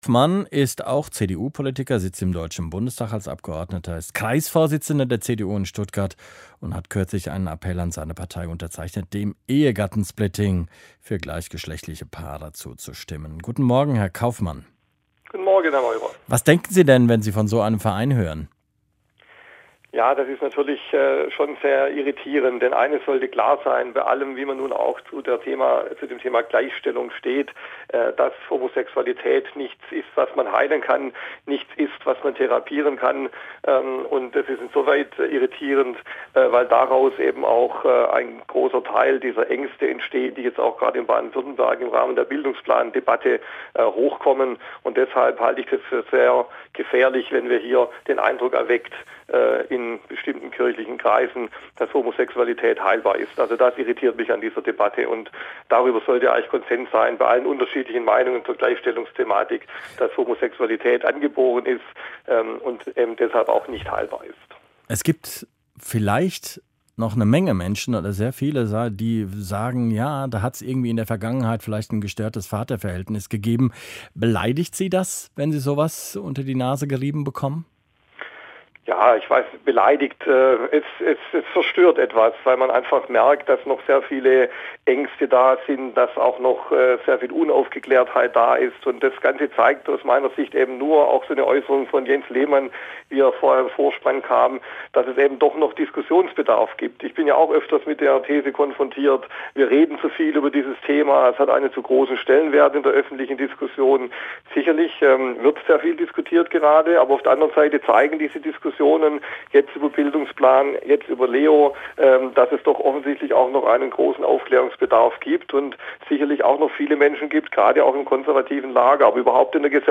Im Deutschlandfunk hat sich heute Morgen der bekennende Homosexuelle CDU-Politiker Stefan Kaufmann zum Thema geäußert: „Homosexualität ist angeboren und kann nicht therapiert werden.“ Der Vorstoß zeige, so Kaufmann, dass es noch großen Aufklärungsbedarf gebe.